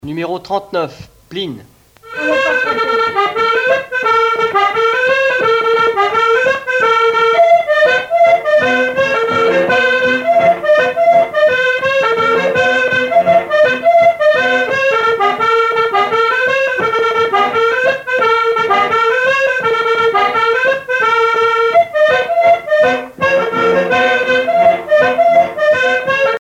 danse : plinn
Pièce musicale éditée